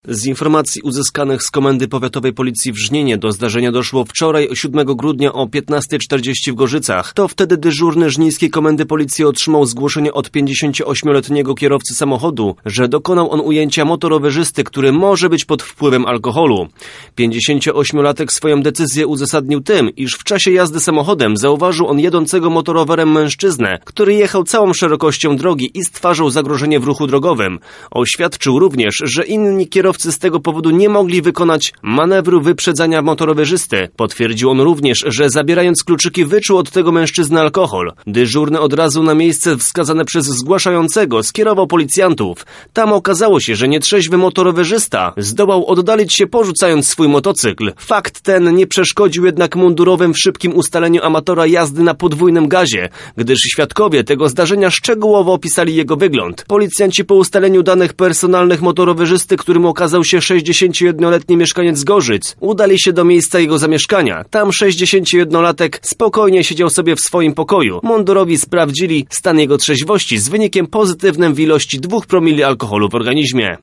Więcej o całym zajściu wie nasz reporter